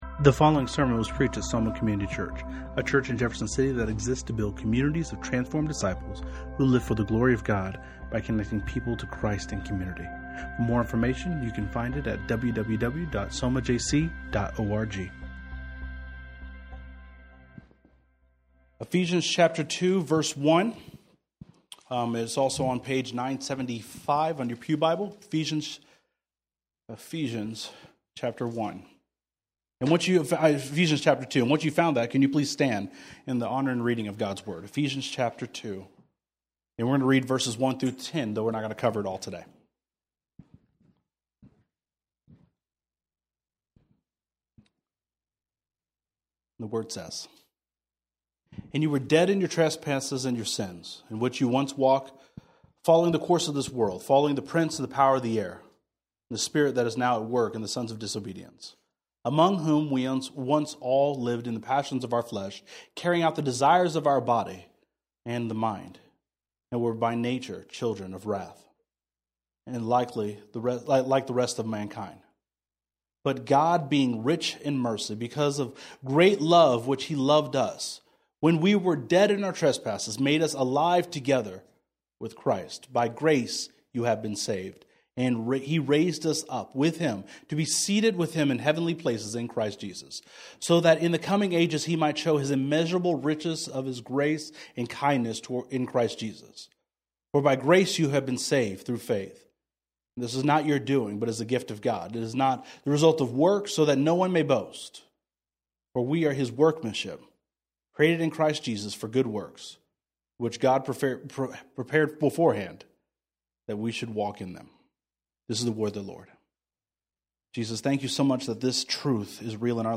“Where do I belong?” is our new sermon series as we are walking through the book of Ephesians.